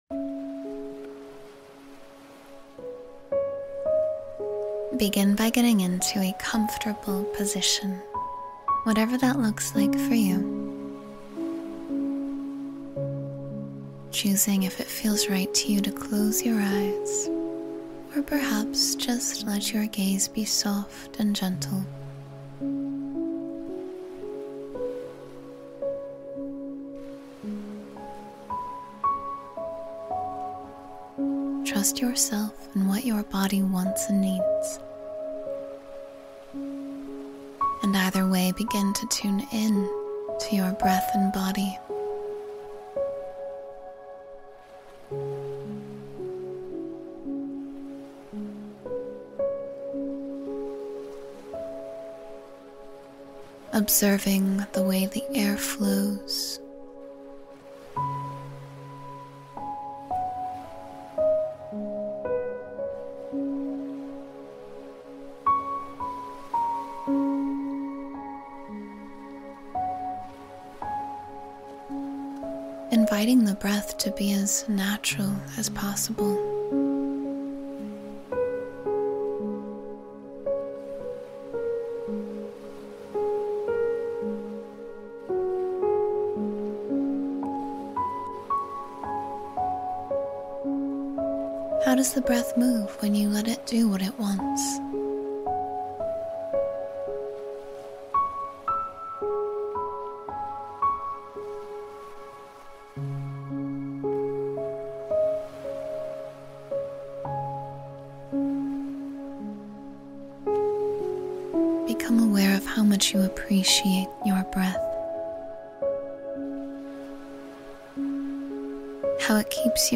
A Calming Beach Visualization